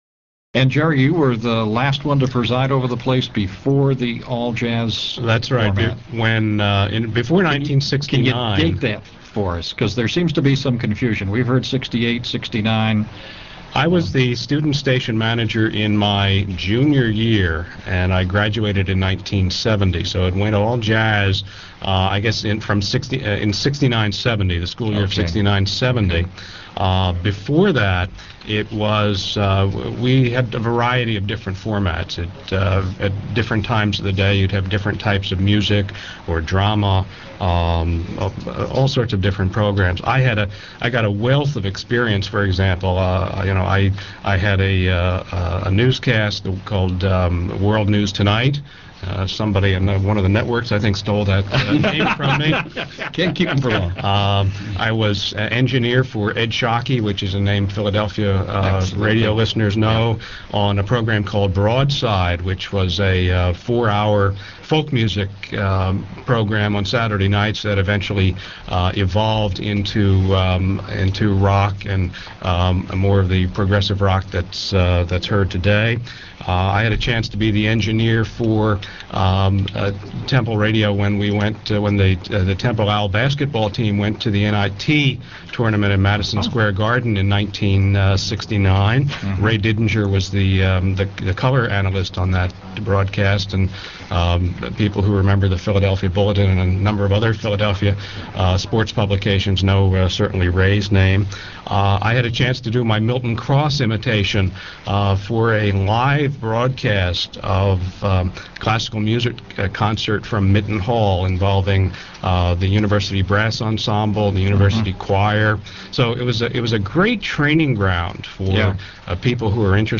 Here are excerpts from that broadcast: